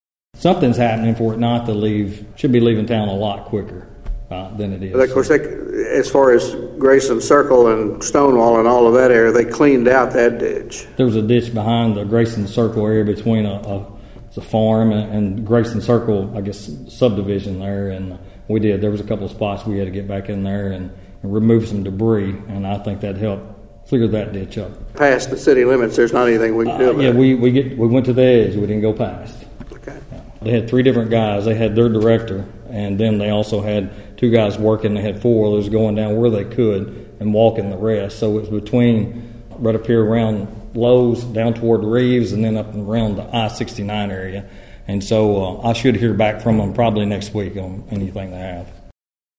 The City Manager said the search is on to discover why rainwater is backing up in the city.(AUDIO)